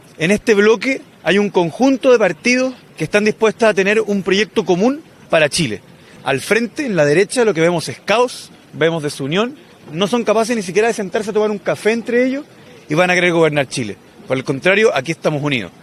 Pasadas las siete de la mañana, el candidato presidencial del Frente Amplio, Gonzalo Winter, llegó hasta la comuna de Estación Central para liderar un banderazo a las afueras del metro.